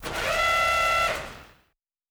pgs/Assets/Audio/Sci-Fi Sounds/Mechanical/Servo Big 7_1.wav at master
Servo Big 7_1.wav